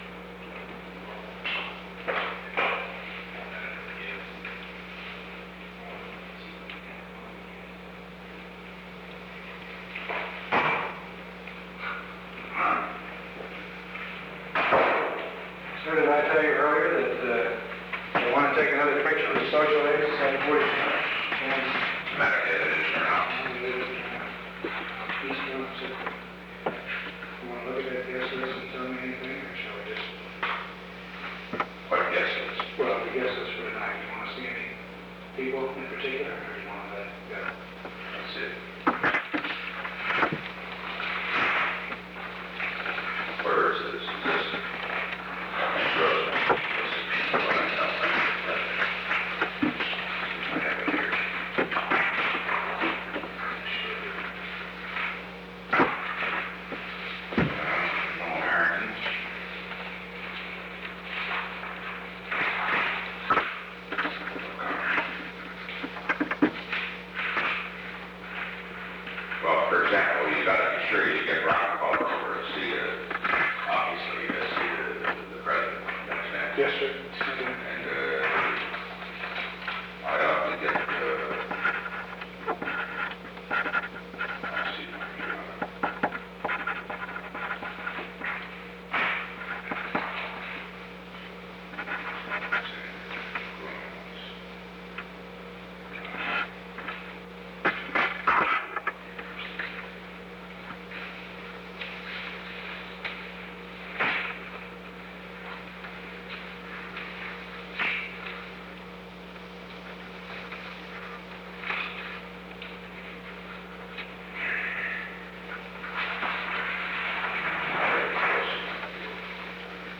Recording Device: Oval Office
On December 7, 1971, President Richard M. Nixon and Alexander P. Butterfield met in the Oval Office of the White House at an unknown time between 6:10 pm and 6:28 pm. The Oval Office taping system captured this recording, which is known as Conversation 631-009 of the White House Tapes.